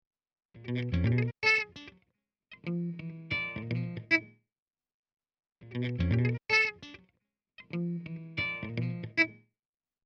guitar hit 7 10 sec. mono 100k
guitarhit7.mp3